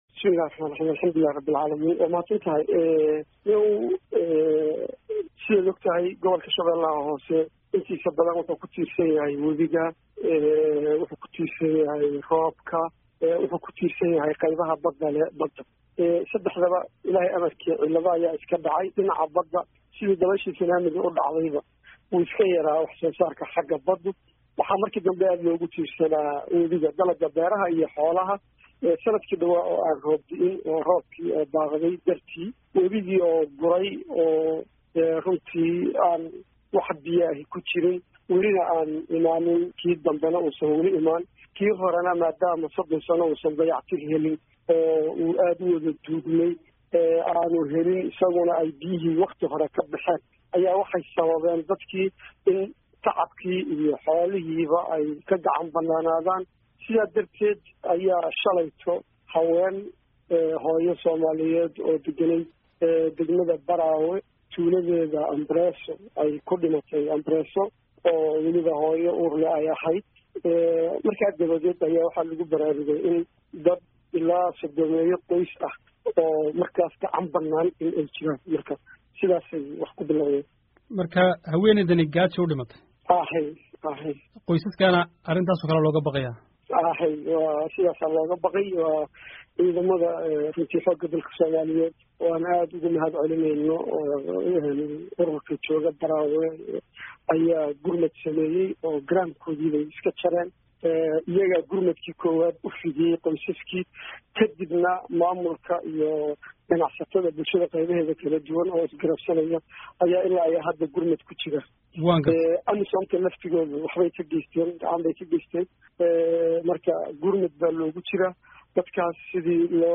Baraawe(INO)- Gudoomiyaha Degmada Baraawe ee Maamulka Gobolka Sh/Hoose Xuseen Maxamed Barre Jeex oo Wareysi siiyay Idaacada Risaala ee Magaalada Muqdisho ayaa ku baaqay in loo gurmado dadka ku dhibaateysay degmada Baraawe.